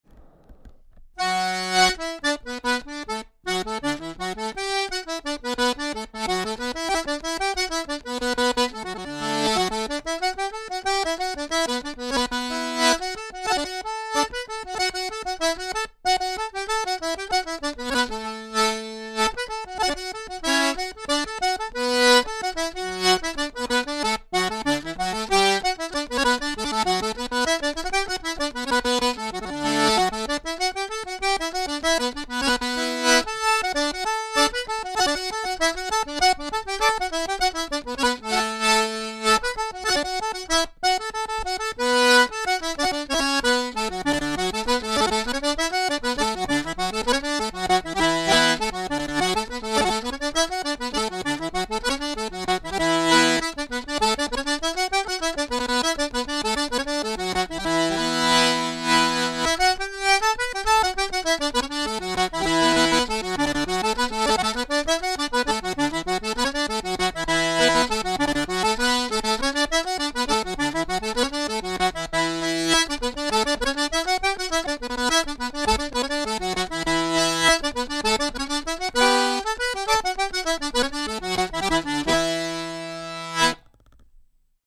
Accordéon "Emeraude"
Modèle d’accordéon « 1 rangée 2 basses » réalisé en noyer massif marqueté et équipé d’anches BlueStar de Voci Armoniche.
• 11 touches à 4 voix